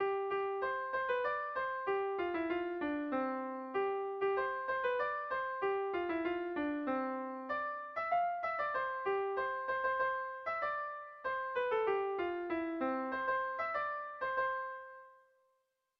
Zortziko txikia (hg) / Lau puntuko txikia (ip)
AABA